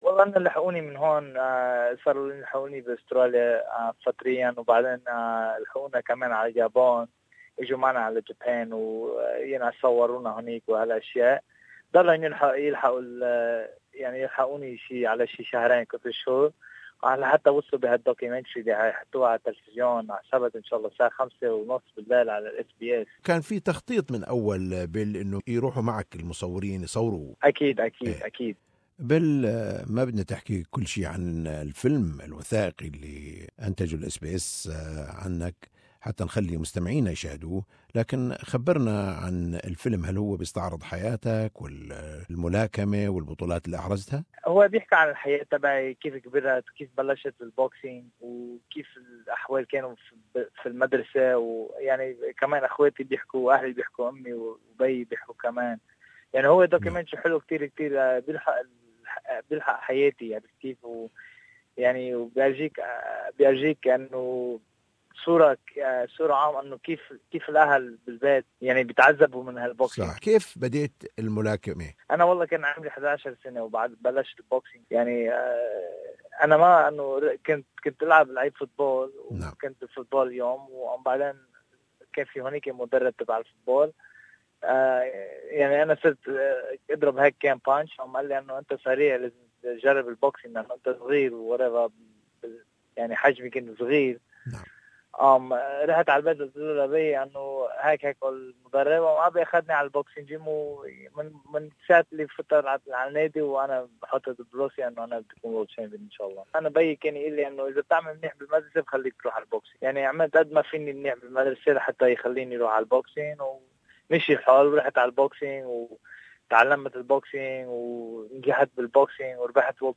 The documentary follows Dib as he questions the future of his career, and reflects on his life growing up in multicultural Australia in this interview Billy speaks about SBS documentary .